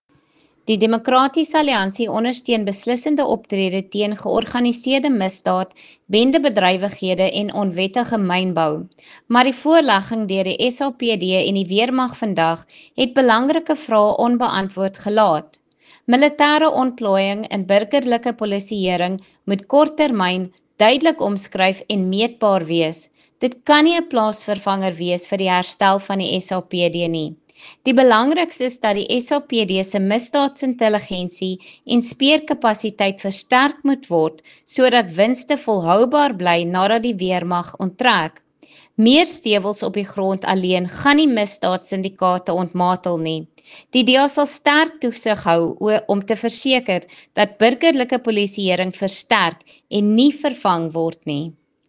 Afrikaans soundbites by Lisa Schickerling MP.